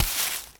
sweeping_broom_leaves_stones_09.wav